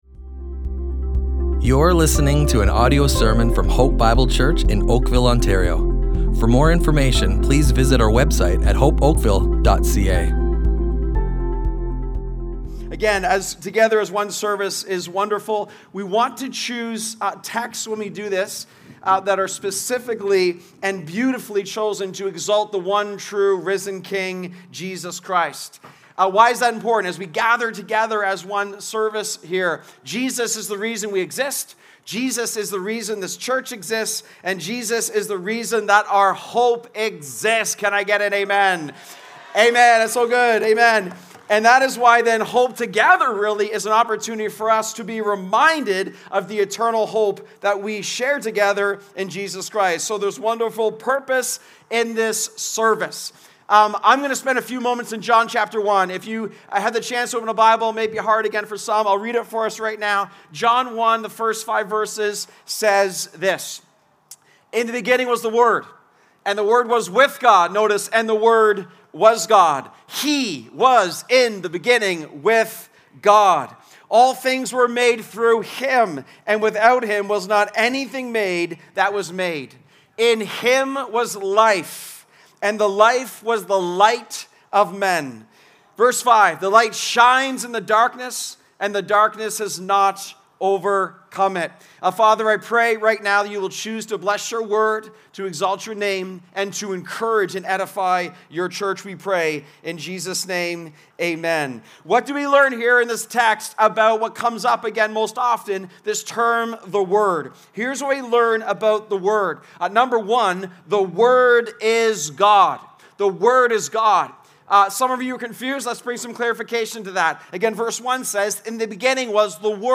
Hope Bible Church Oakville Audio Sermons Hope Together 2025 // The Awesome Word of God!